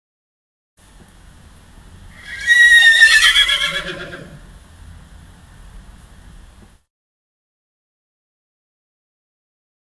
Лошадь скачет под порывами ветра и дождя, а вдалеке гремит гром